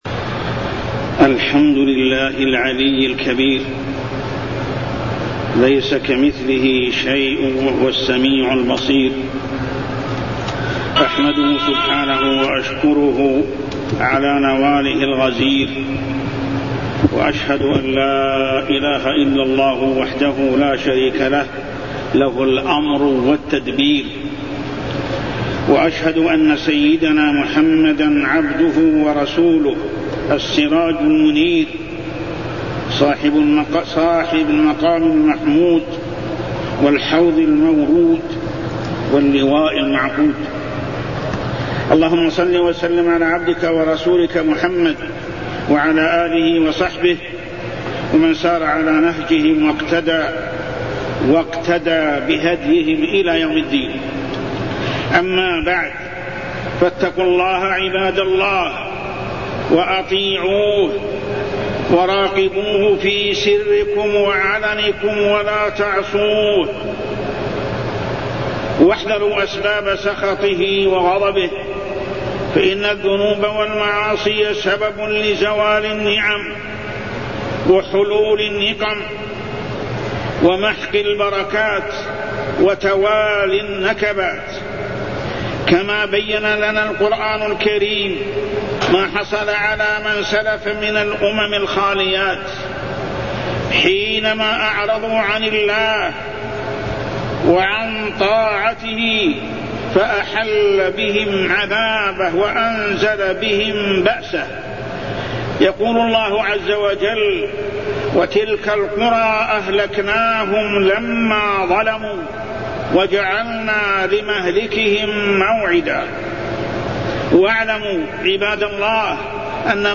تاريخ النشر ٩ محرم ١٤٢١ هـ المكان: المسجد الحرام الشيخ: محمد بن عبد الله السبيل محمد بن عبد الله السبيل نجاة موسى عليه السلام من فرعون